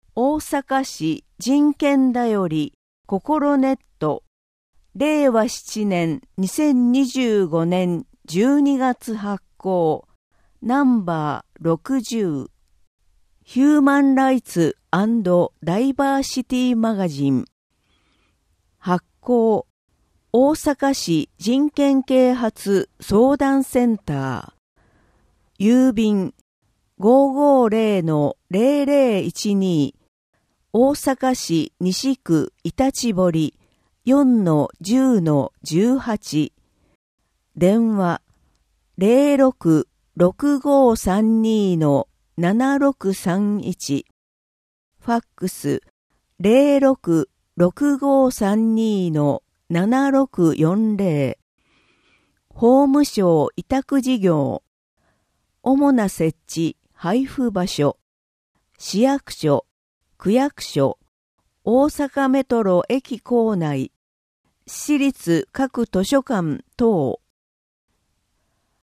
本号につきましては、淀川区で活動されている「音訳ボランティアグループこもれび」の有志の皆様に作成いただきました。